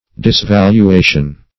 Disvaluation \Dis*val`u*a"tion\, n. Disesteem; depreciation; disrepute.